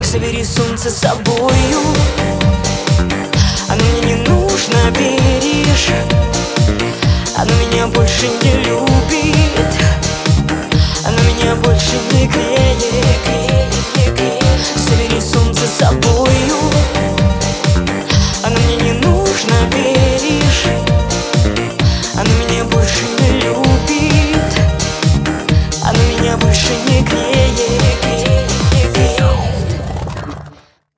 • Качество: 256, Stereo
ритмичные
попса
русская попса